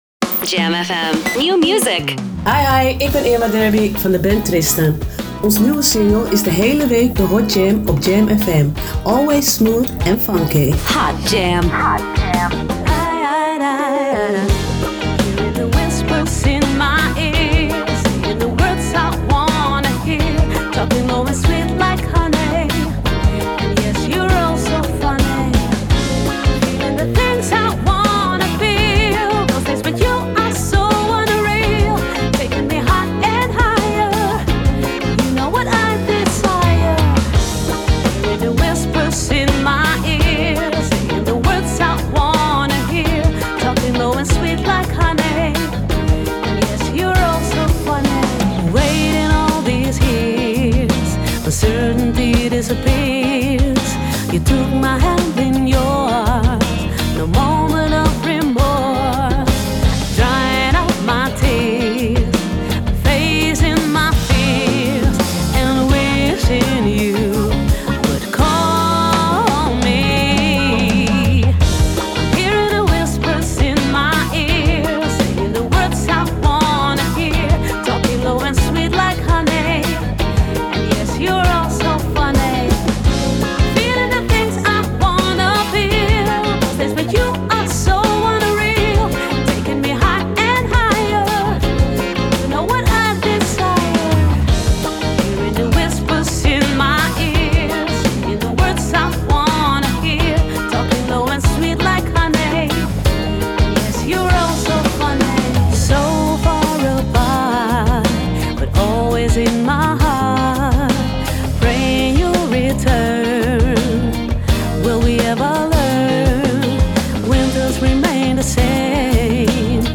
prachtige en soulvolle zang